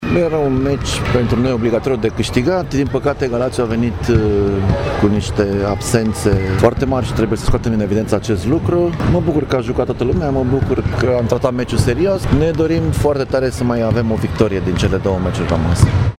La finele jocului cu gălățenii